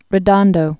(rĭ-dŏndō)